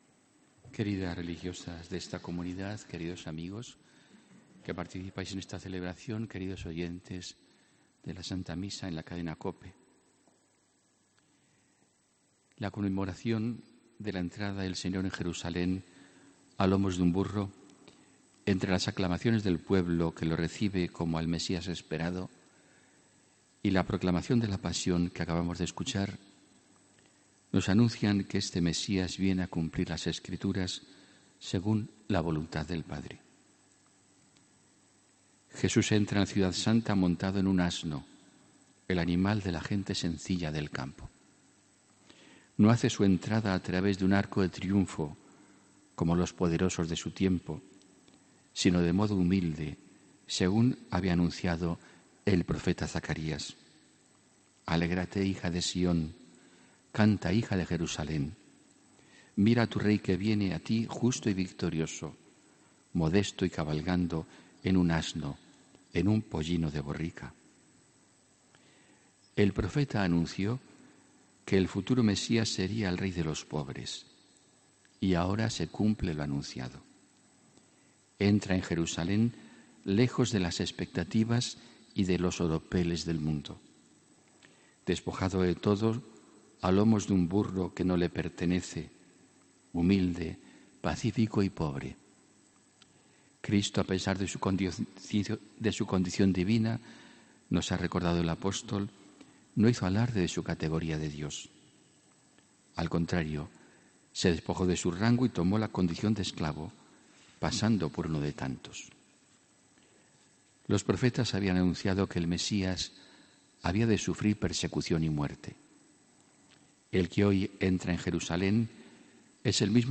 HOMILÍA 25 MARZO